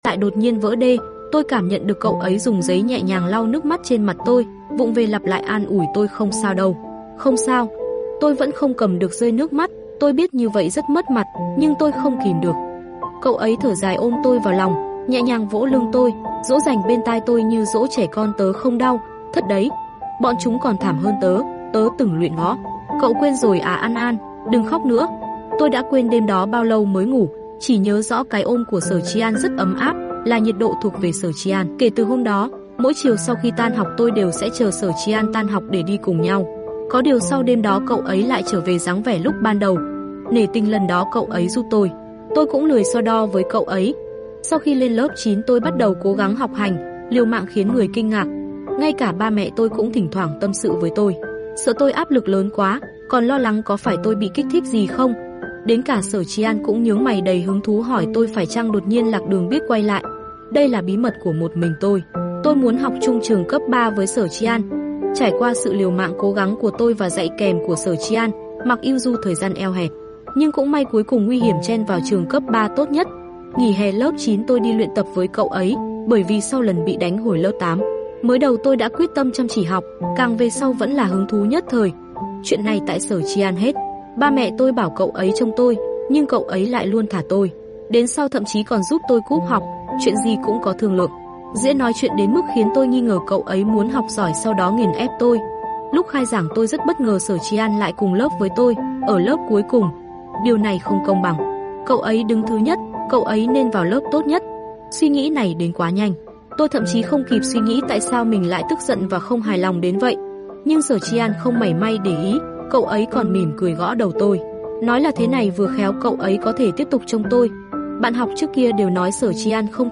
TRUYỆN AUDIO | mai táng sound effects free download